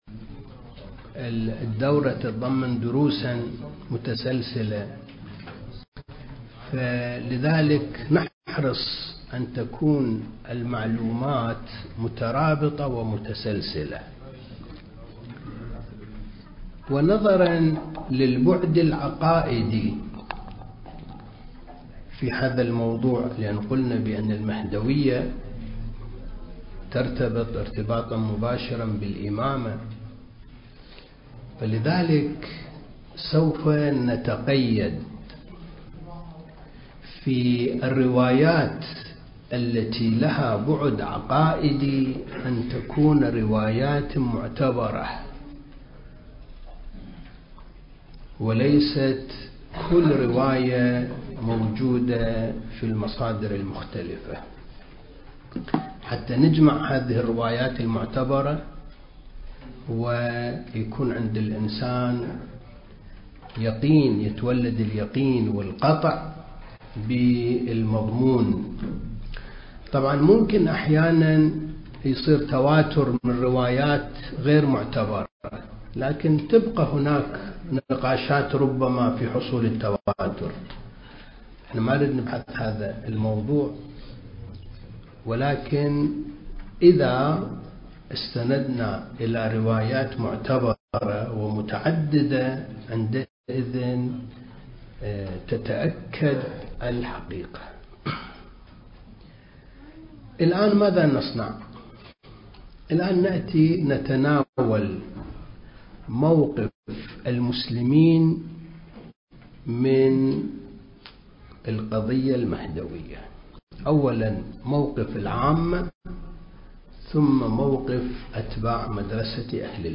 دورة الثقافة المهدوية (2) المكان: معهد وارث الأنبياء (عليهم السلام) لإعداد المبلغين العتبة الحسينية المقدسة